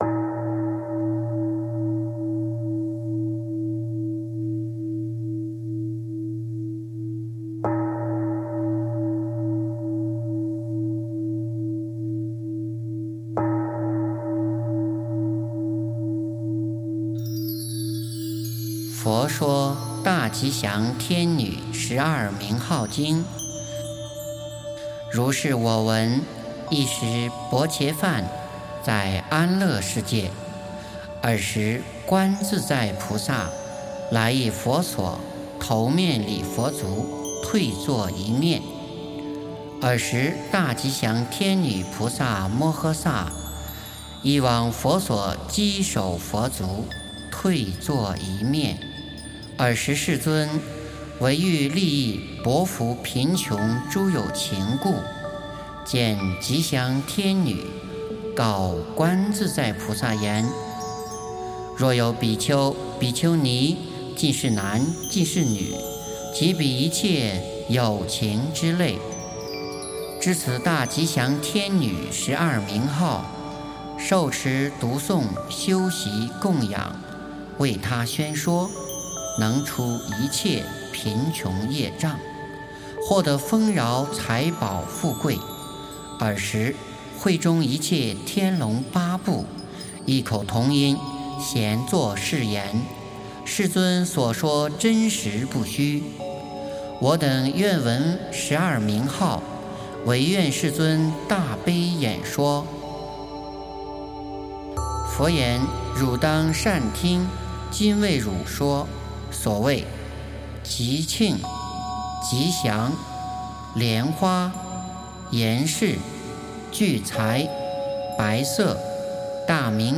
诵经
佛音 诵经 佛教音乐 返回列表 上一篇： 心经(天籁梵音